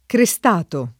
vai all'elenco alfabetico delle voci ingrandisci il carattere 100% rimpicciolisci il carattere stampa invia tramite posta elettronica codividi su Facebook crestato [ kre S t # to ] (antiq. cristato [ kri S t # to ]) agg.